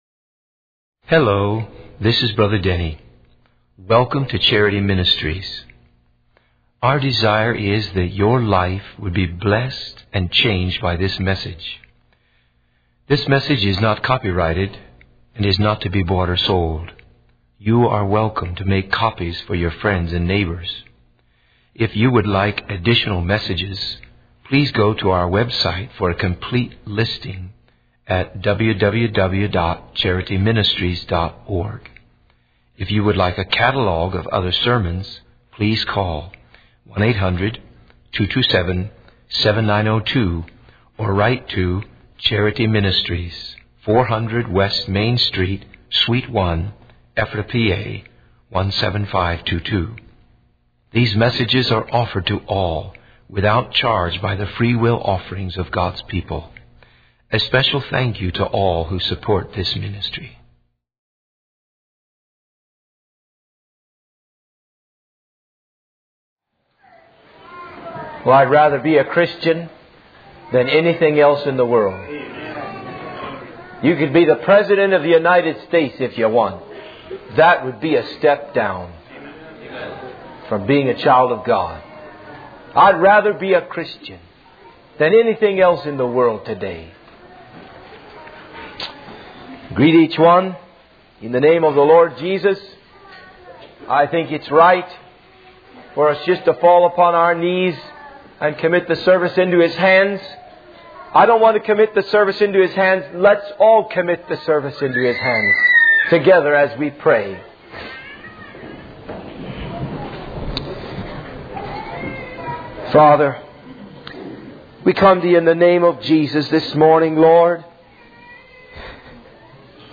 In this sermon, the speaker shares his personal testimony of how God transformed his life. He emphasizes that God works in mysterious ways and leads us on a divine path.